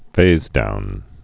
(fāzdoun)